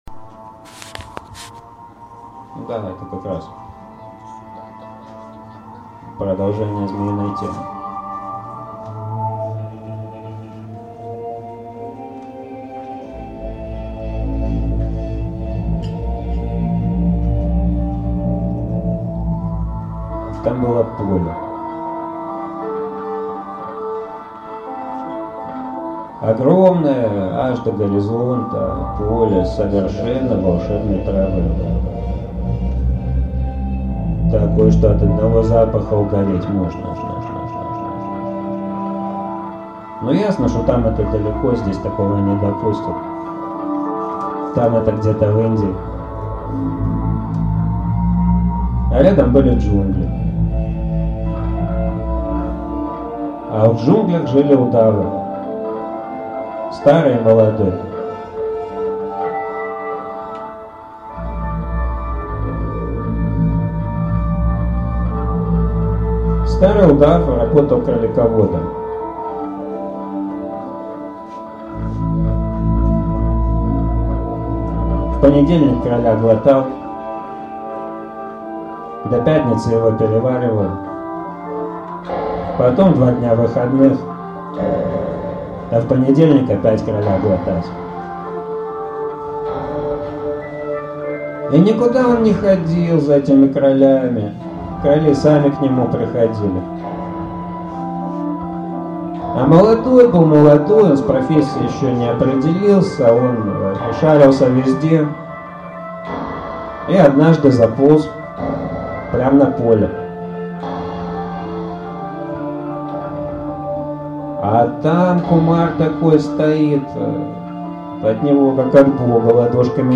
Аудиокнига Про удава | Библиотека аудиокниг